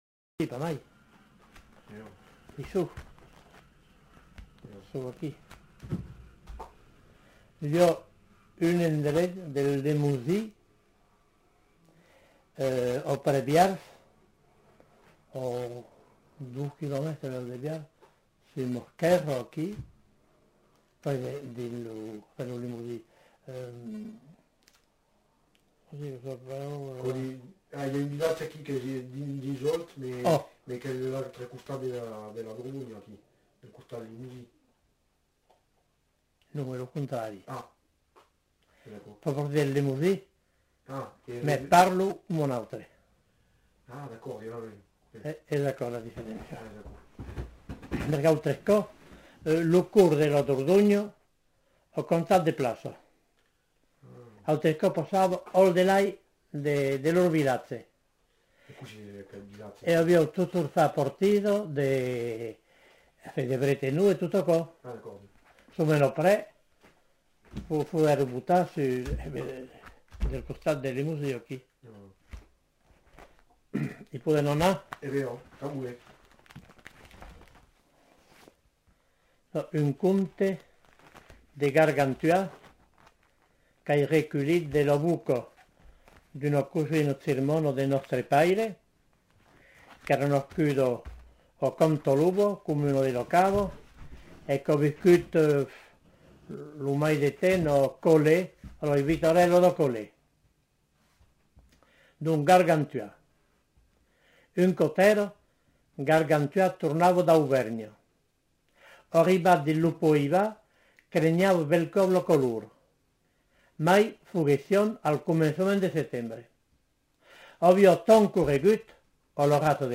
Lieu : Rocamadour
Genre : conte-légende-récit
Type de voix : voix d'homme Production du son : lu Classification : récit légendaire